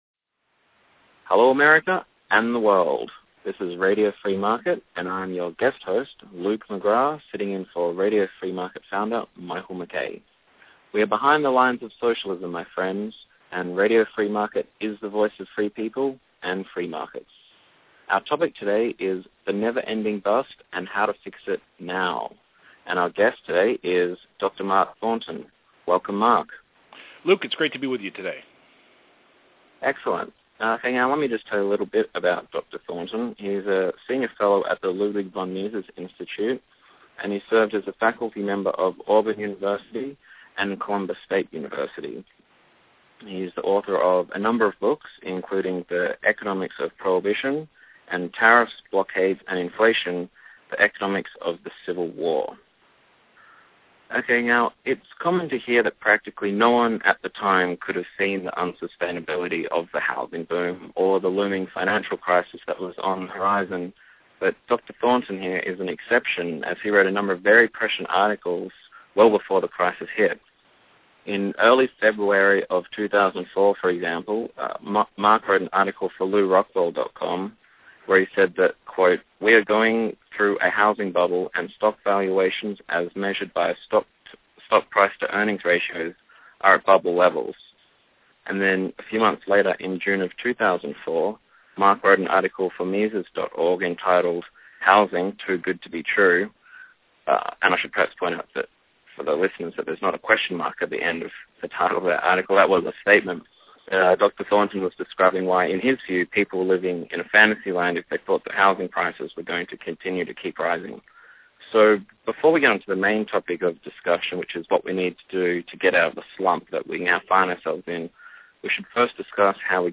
Please join us for this very important interview and please share it widely.